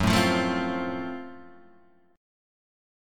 GbmM7b5 chord